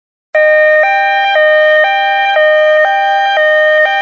Ambulance Siren Sound Effect Free Download
Ambulance Siren